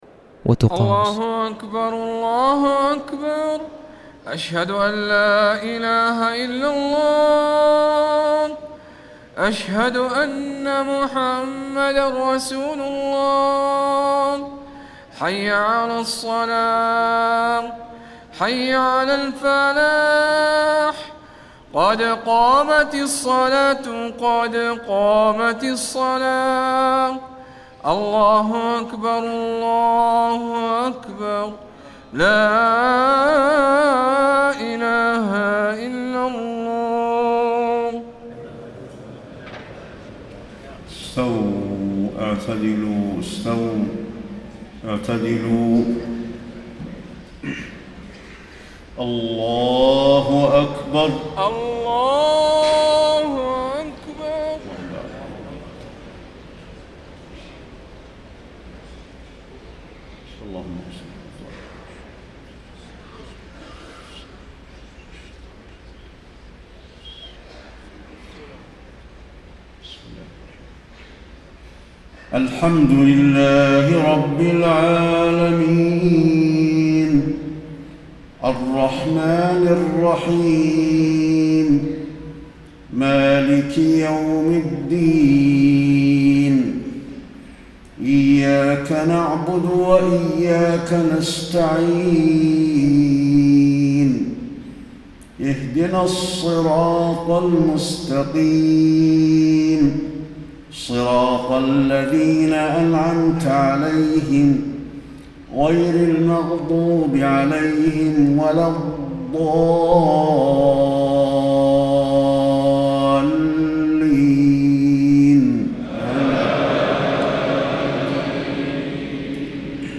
صلاة العشاء 4-7-1434هـ خواتيم سورة الفرقان > 1434 🕌 > الفروض - تلاوات الحرمين